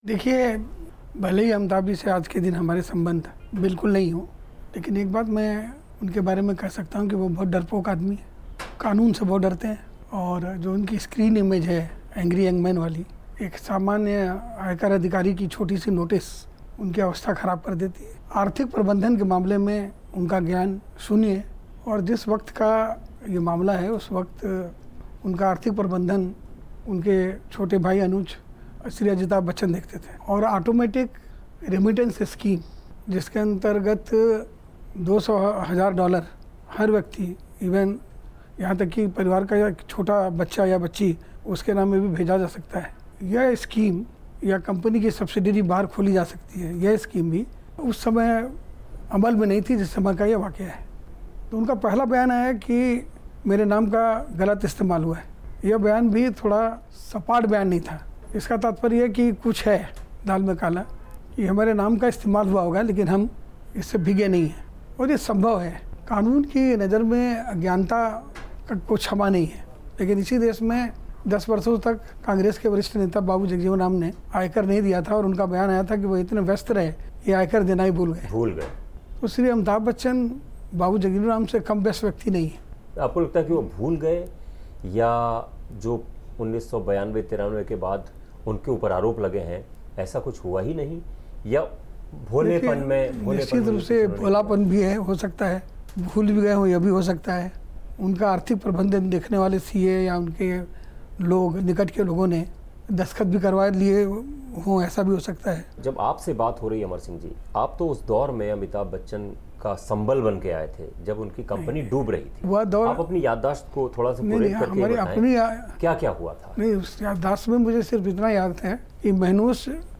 अमर सिंह से बात की.